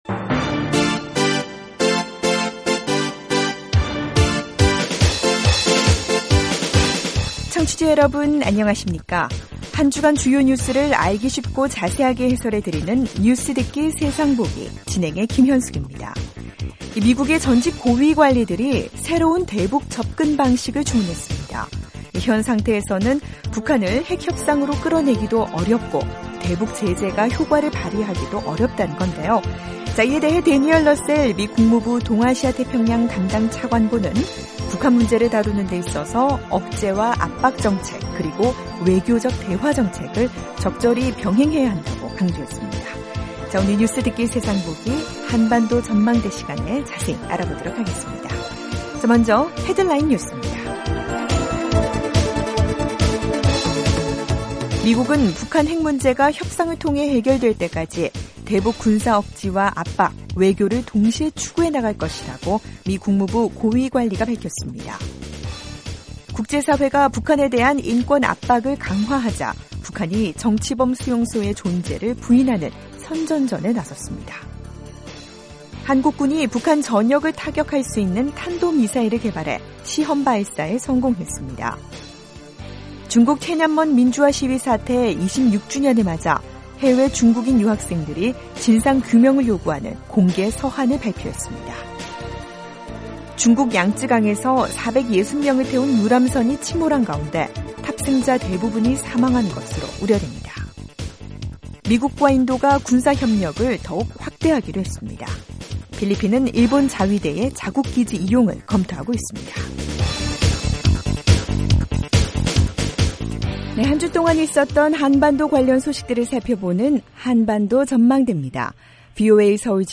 한 주간 주요 뉴스를 알기 쉽고 자세하게 해설해 드리는 '뉴스듣기 세상보기' 입니다. 미국의 전직 고위 관리들이 새로운 대북 접근방식을 주문했습니다. 중국 톈안먼 민주화 시위 사태 26주년을 맞아, 해외 중국인 유학생들이 진상 규명을 요구하는 공개 서한을 발표했습니다. 중국 양쯔강에서 460명을 태운 유람선이 침몰한 가운데, 탑승자 대부분이 사망한 것으로 우려됩니다.